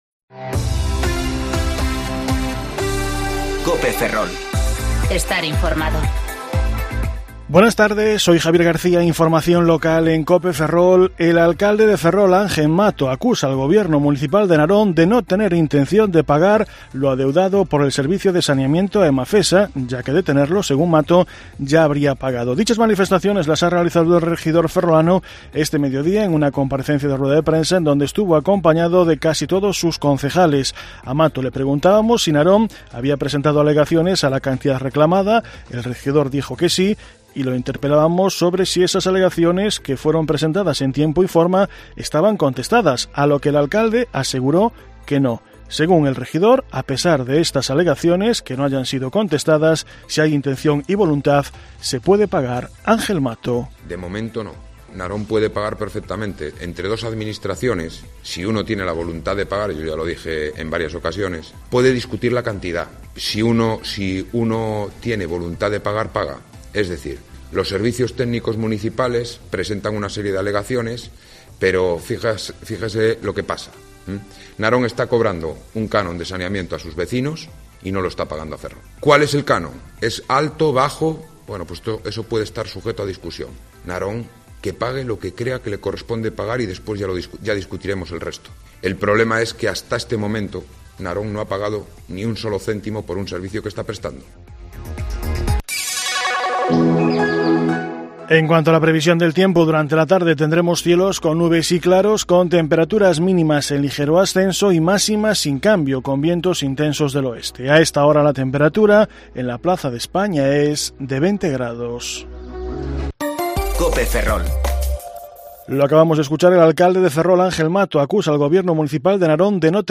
Informativo Mediodía Cope Ferrol 24/9/2019 (De 14.20 a 14.30 horas)